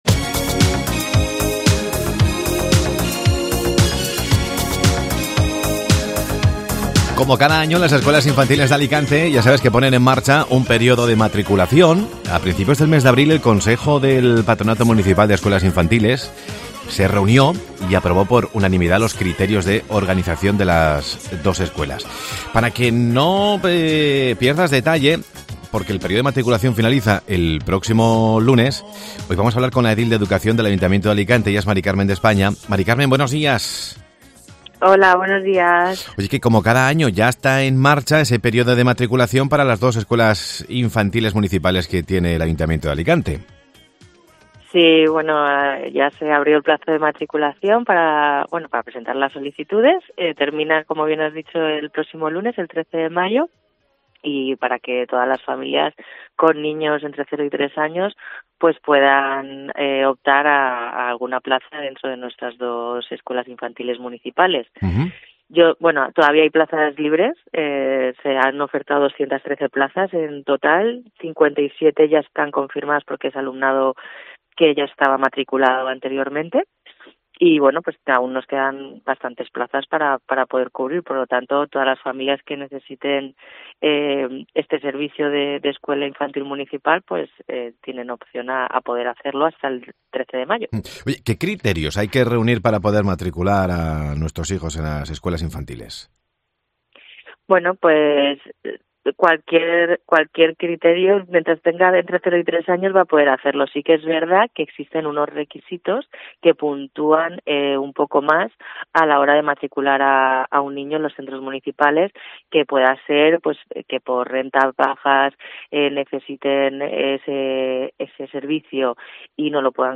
Entrevista MªCarmen de España, edil de Educación del Ayuntamiento de Alicante